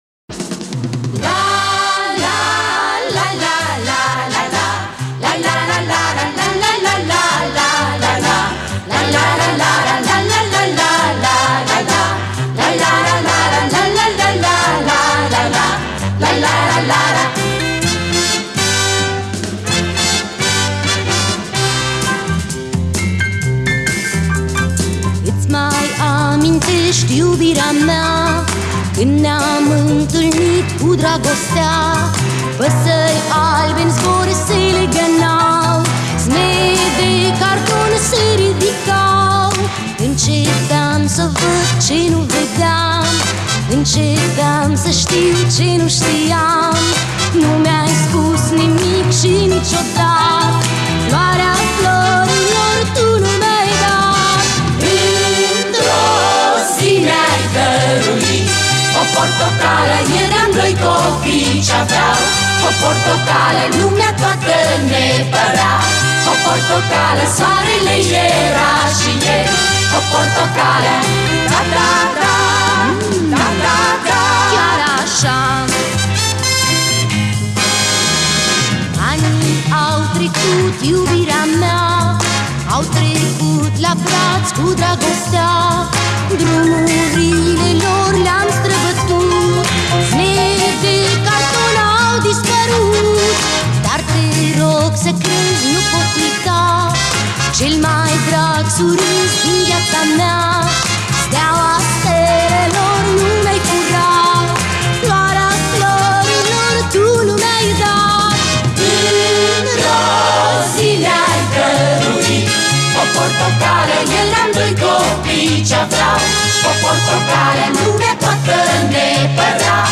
a fost o actriţă şi interpretă de muzică uşoară şi folk.